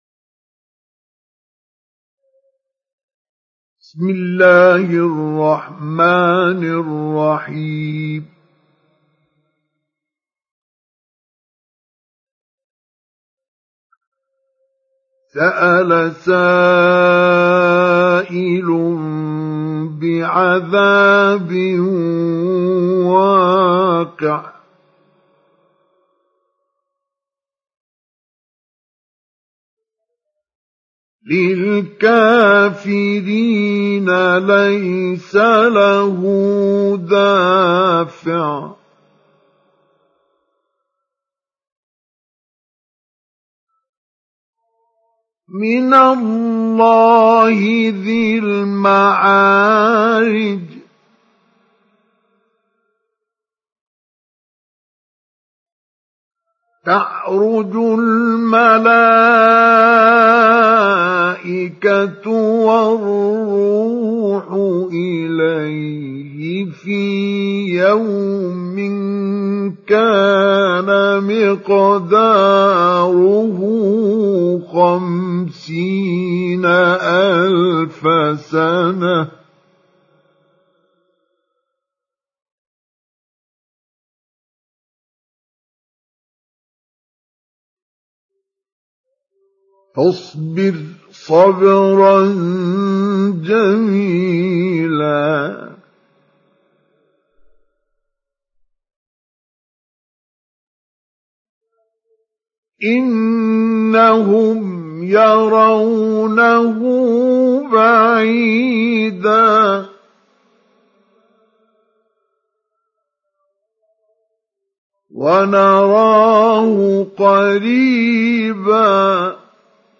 سُورَةُ المَعَارِجِ بصوت الشيخ مصطفى اسماعيل